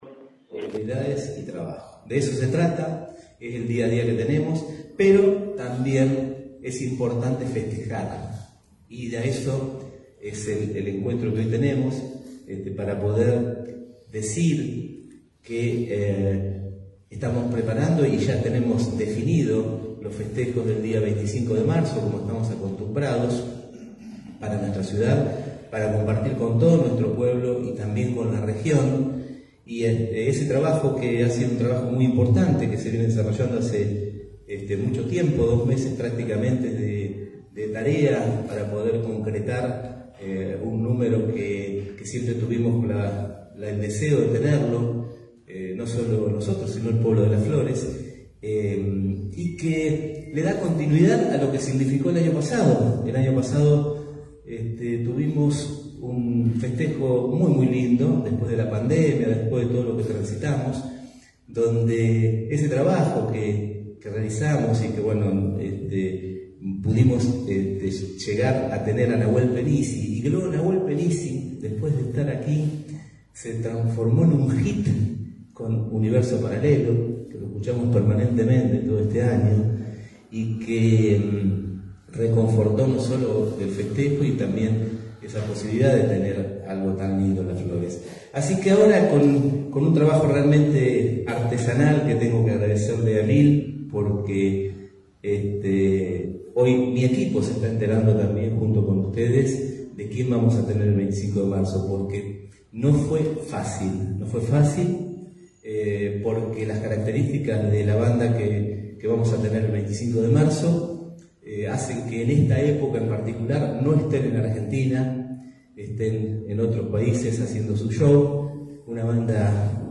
Así fue confirmado este viernes en conferencia de prensa por el intendente Alberto Gelené.
Gelene-anuncia-festejos.mp3